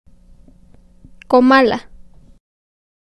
Comala (Spanish: [koˈmala]
ComalaPronunciation.ogg.mp3